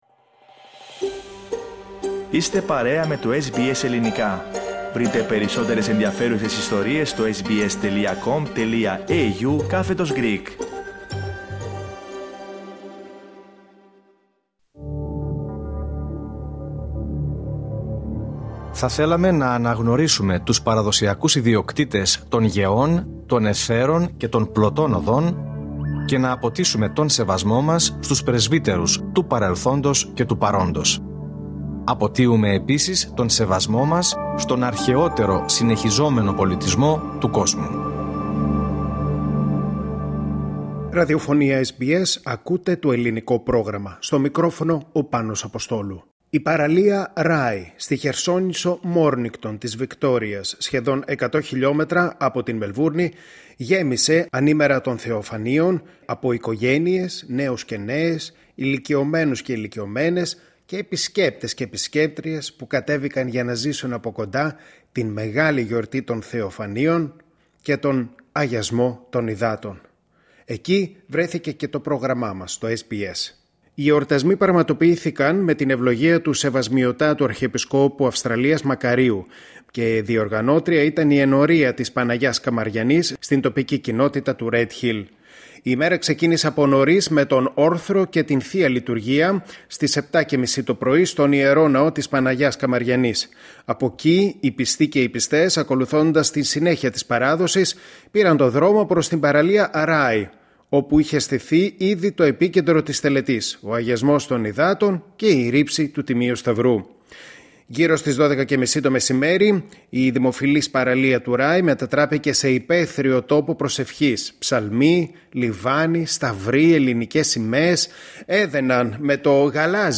Η παραλία του Rye, στη χερσόνησο Mornington, περίπου 100 χιλιόμετρα από τη Μελβούρνη, συγκέντρωσε το μεσημέρι της Τρίτης 6 Ιανουαρίου 2026 εκατοντάδες πιστούς και επισκέπτες για τον εορτασμό των Θεοφανείων και την τελετή του Αγιασμού των Υδάτων. Το SBS Greek βρέθηκε στο σημείο και κάλυψε τις εκδηλώσεις, που πραγματοποιήθηκαν με την ευλογία του Σεβασμιωτάτου Αρχιεπισκόπου Αυστραλίας κ.κ. Μακαρίου και τη διοργάνωση της Ενορίας Παναγία Καμαριανή (Red Hill).